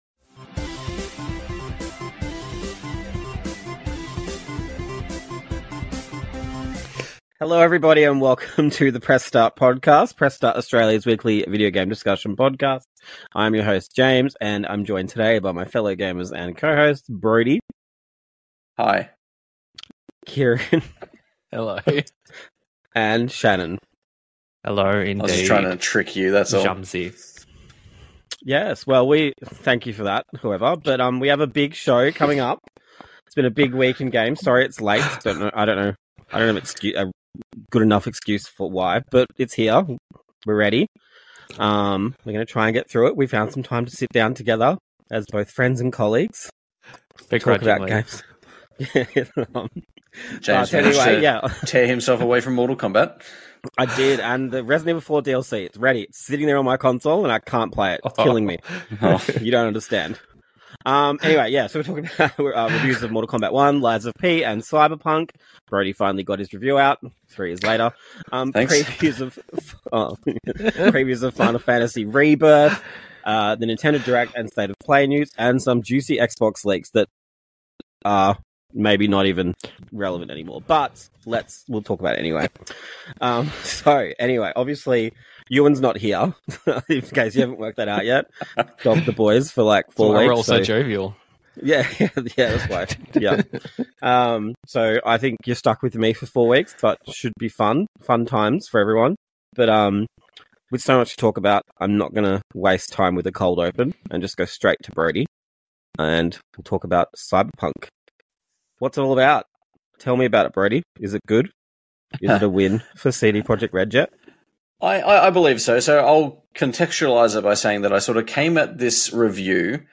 The Press Start Podcast is Press Start Australia's weekly video game discussion podcast.